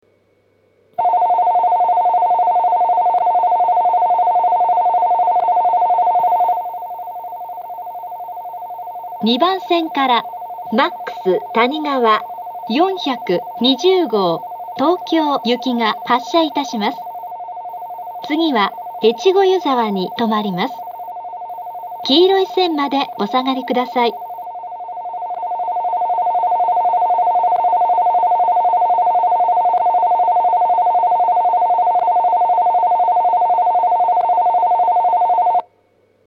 ２番線発車ベル Ｍａｘたにがわ４２０号東京行の放送です。